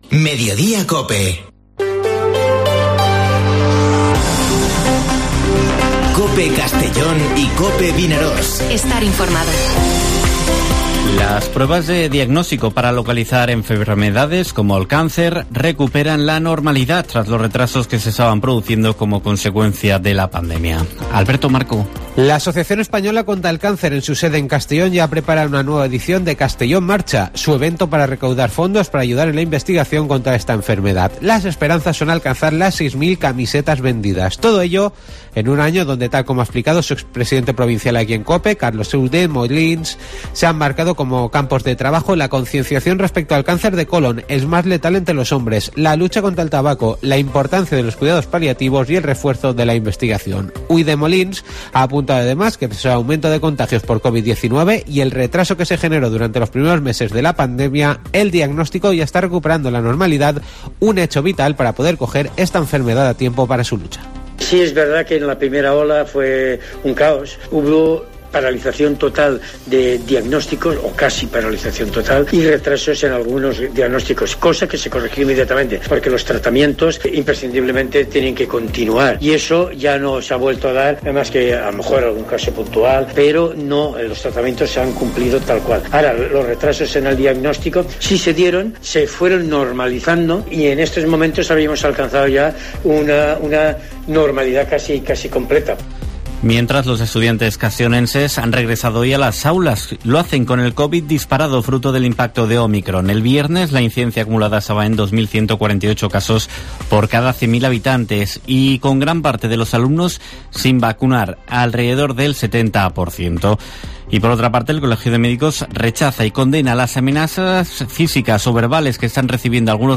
Informativo Mediodía COPE en la provincia de Castellón (10/01/2022)